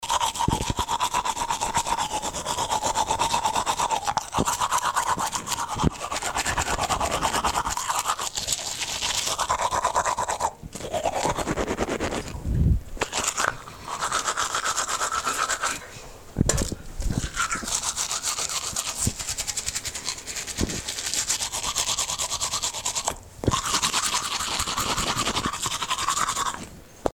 Cepillado de dientes
Breve grabación sonora que capta el sonido de una persona cepillándose los dientes. Se escucha el sonido bucal del roce del cepillo frotando los dientes.
Sonidos: Acciones humanas